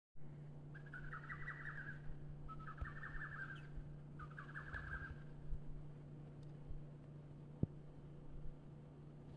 お知らせ 6月21日【SDGs】何という鳥の鳴き声でしょうか？
学校の運動場の奥の林から、野鳥の鳴き声が聞こえてきます。実際に現地で収録した音声があります。
野鳥の鳴き声１.m4a
答えは、「ホトトギス」です。